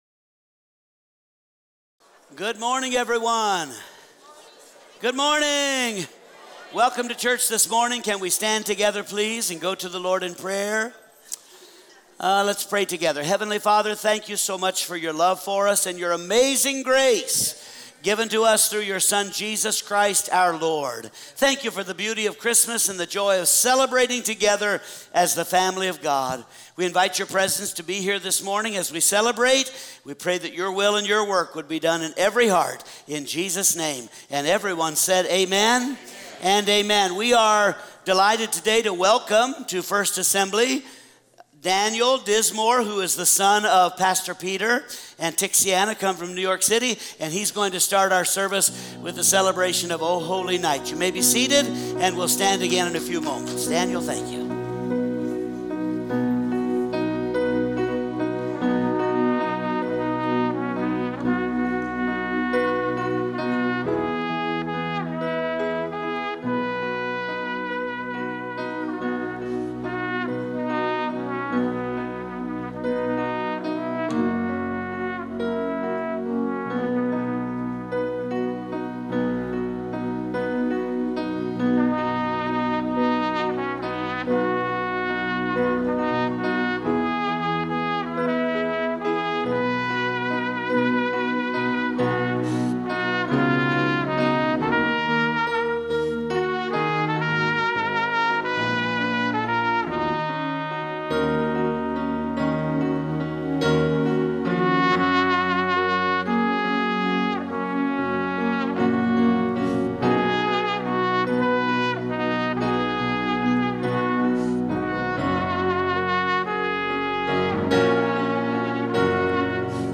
Topic: Show on Home Page, Sunday Sermons